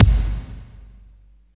29.08 KICK.wav